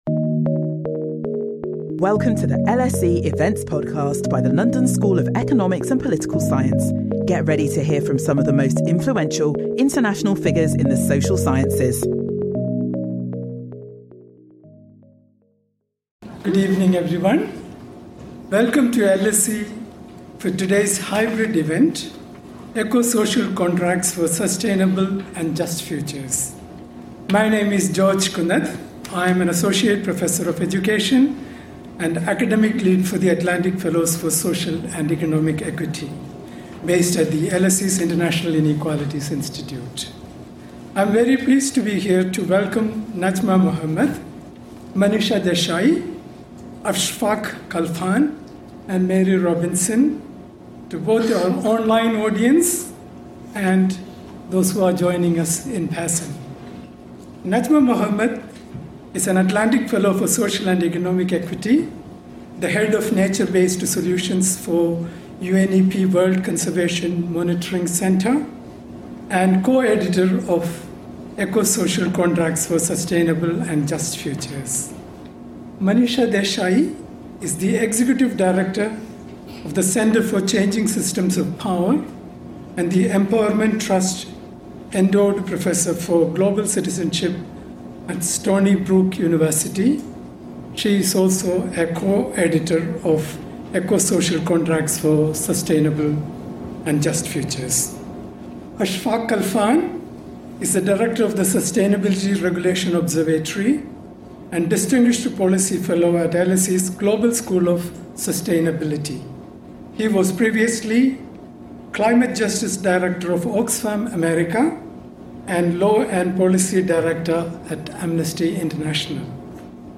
At this event, you will hear from contributors of this ground breaking open-access volume presenting eco-social contracts as a bold and actionable vision for tackling the major, interconnected crises of our time—climate change, biodiversity loss, rising inequality, and the erosion of public trust and democratic legitimacy. At its core lies a crucial realisation that can no longer be ignored: the social contract has been broken for billions of people.